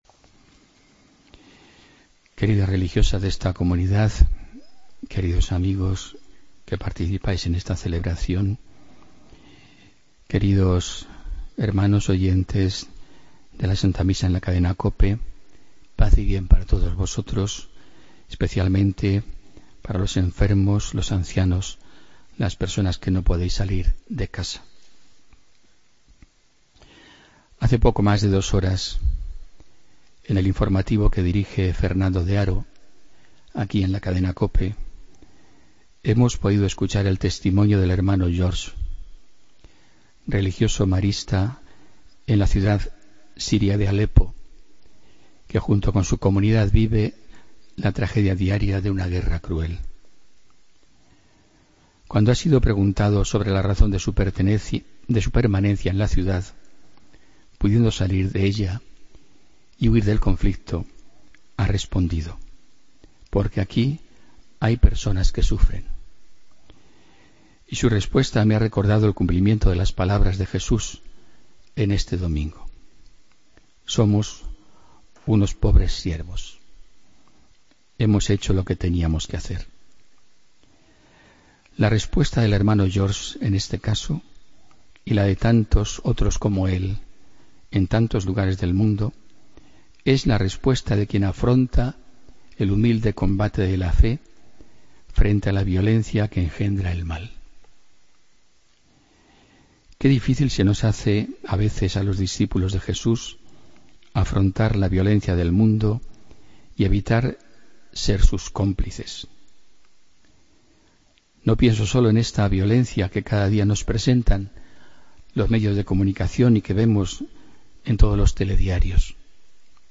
Homilía del domingo 2 de octubre de 2016